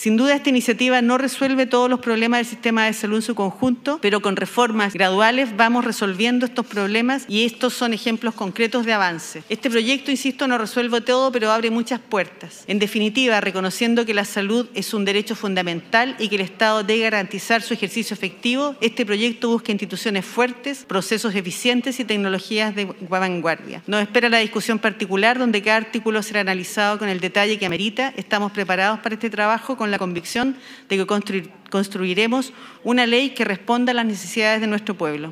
La ministra de Salud, Ximena Aguilera, agradeció el respaldo y enfatizó que se trata de “una iniciativa integral, que fortalece a un conjunto de instituciones clave para mejorar la atención de los pacientes”.